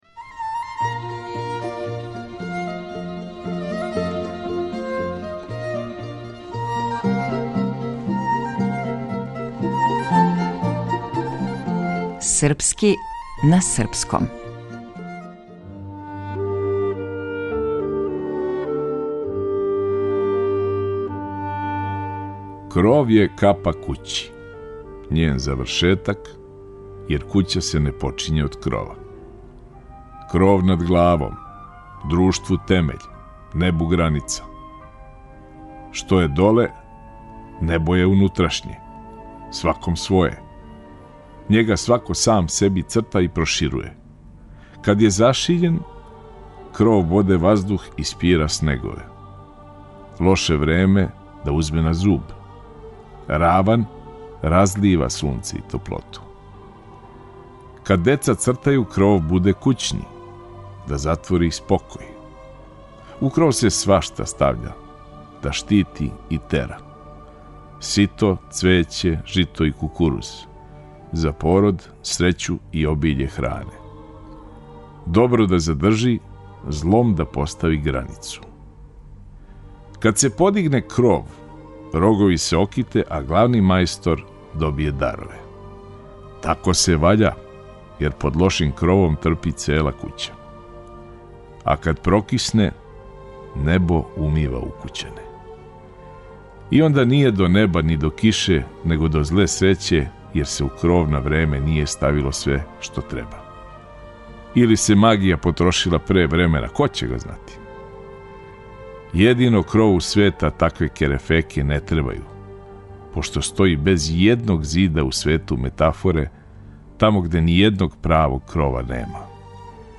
Глумац - Никола Којо